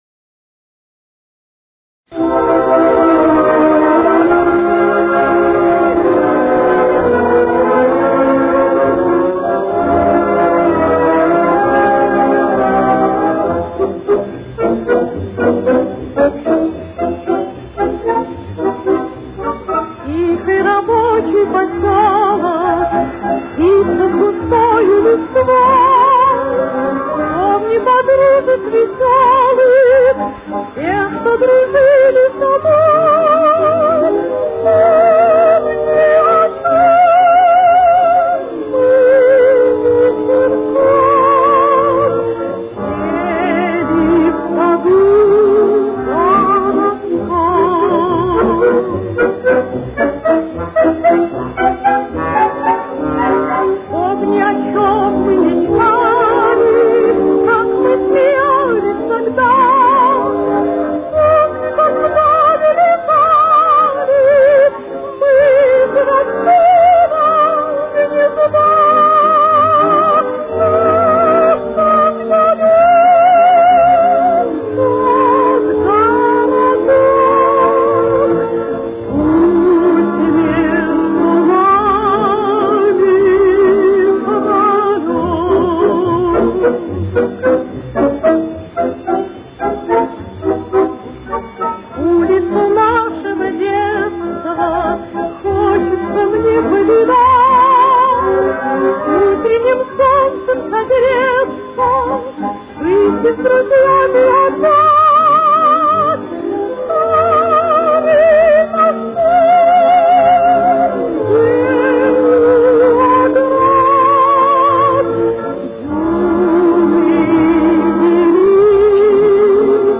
Фа минор. Темп: 196.